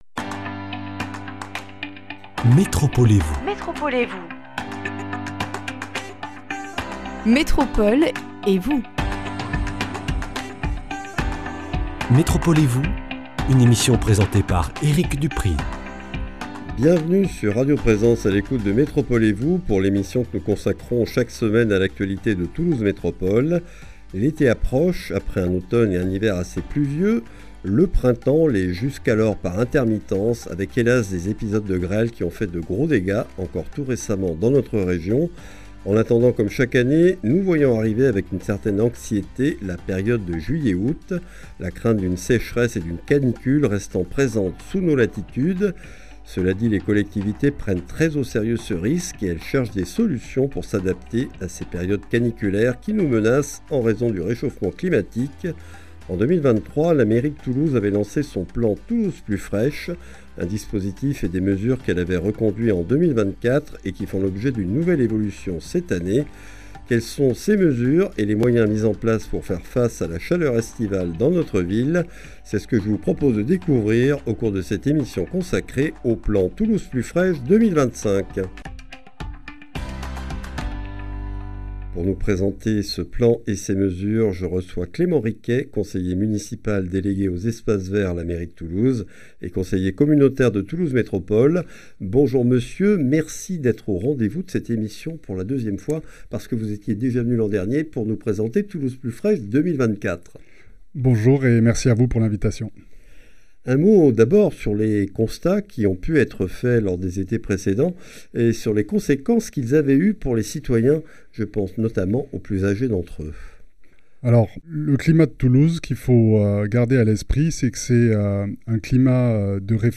Clément Riquet, conseiller municipal délégué à la mairie de Toulouse (espaces verts), secrétaire de la commission Écologie, Développement Durable et Transition énergétique de Toulouse Métropole, nous présente l’esprit de cette démarche, la manière dont le plan a été conçu ainsi que ses objectifs et mesures principales.